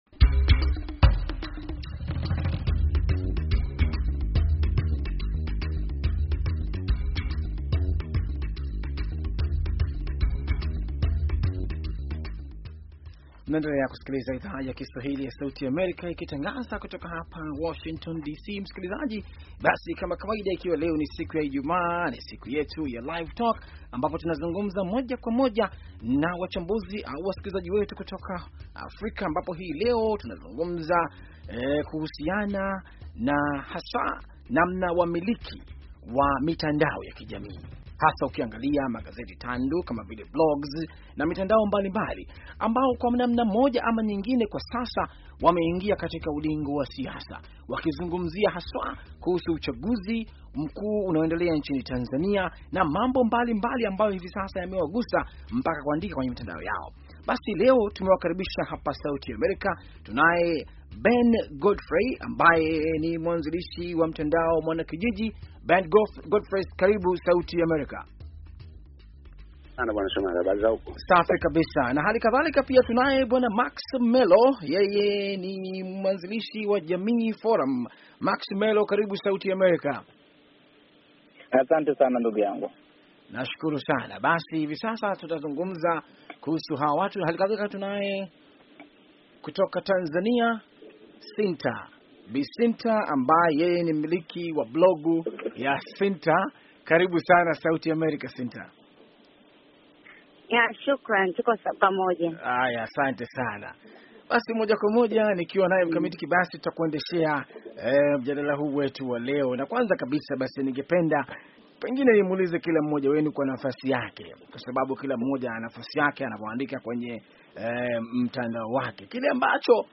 Mjadala wa Ijumaa wamiliki wa mitandao na uchaguzi.